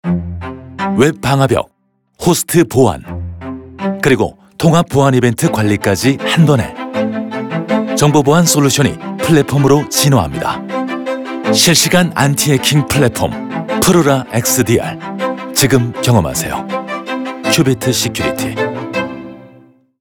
📻 라디오